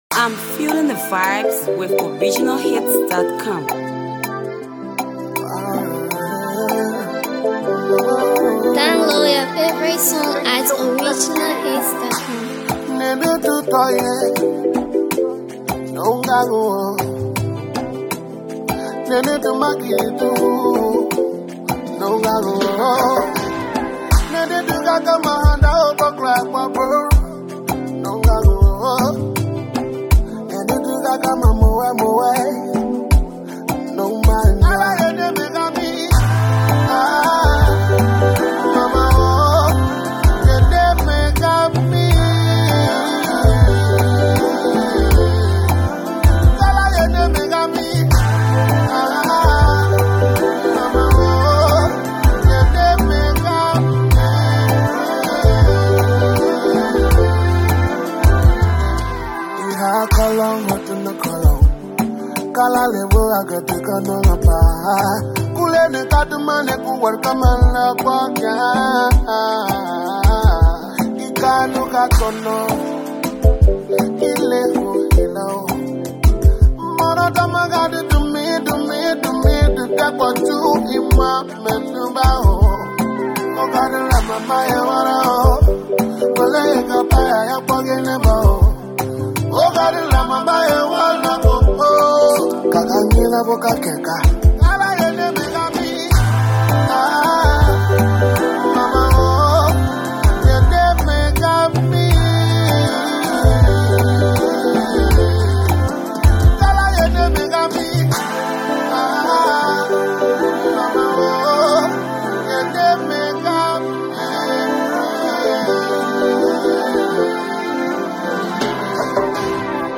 Liberian vocalist